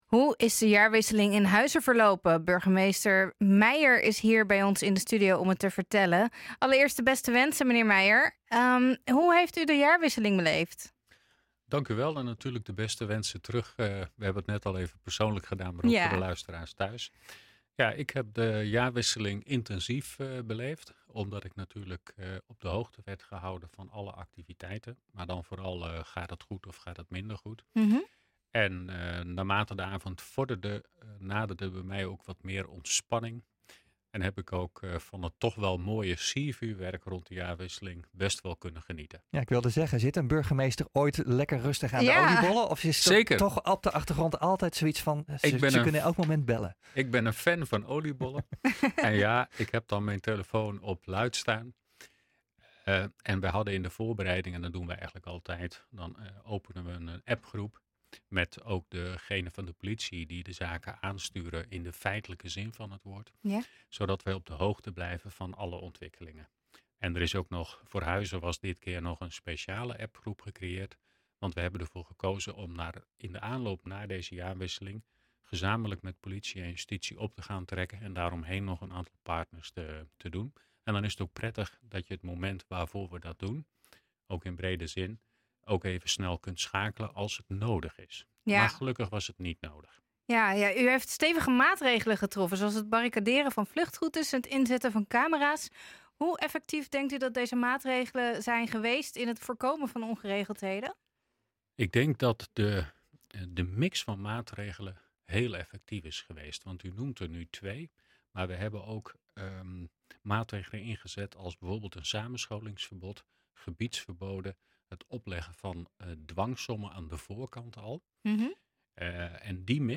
U luistert nu naar NH Gooi Zaterdag - Burgemeester Niek Meijer over jaarwisseling
burgemeester-niek-meijer-over-jaarwisseling.mp3